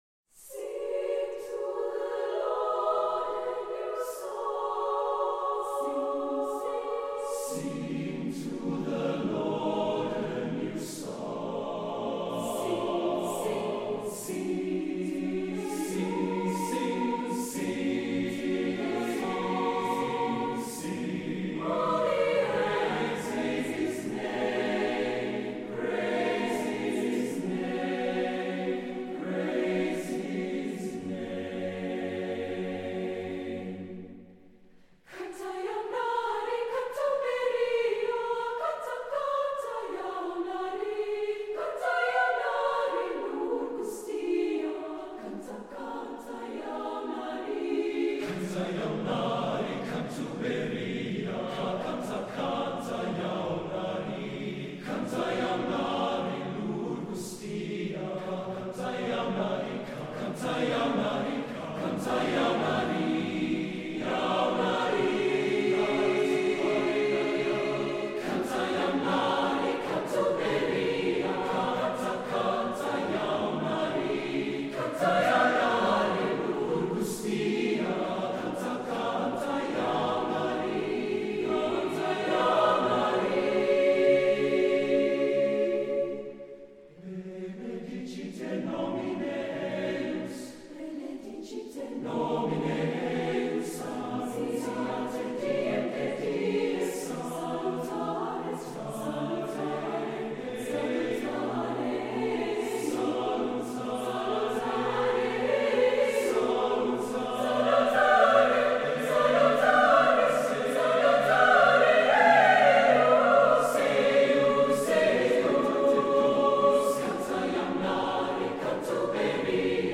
stellenbosch-university-choir-cantate-dominomp3mp3.mp3